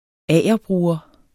Udtale [ ˈæˀjʌˌbʁuːʌ ]